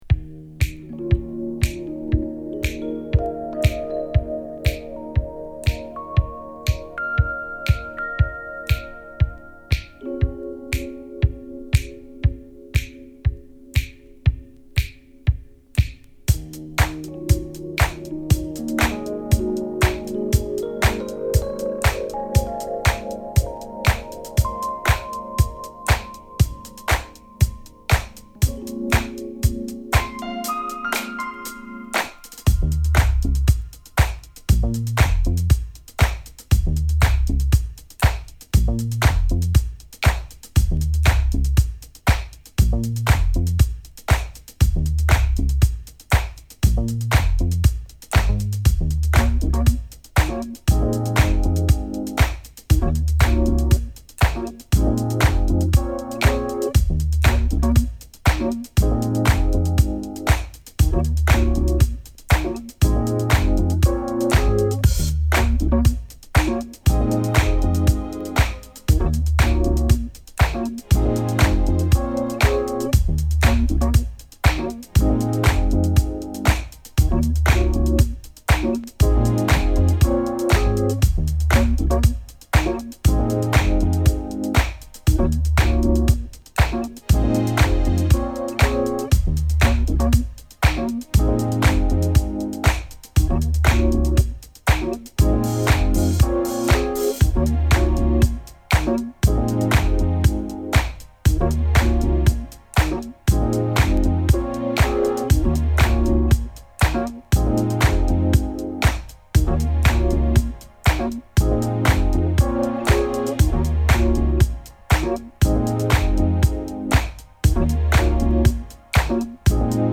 キレのあるクラップ・ビートに、シンセベースと鮮やかなキーボードが煌めくビートダウンハウスなSideA
しなやかなクラップ・ディスコ・ビートに、ウォーミーでメローなキーボードが心地よいムードを広げるフュージョン・ブギー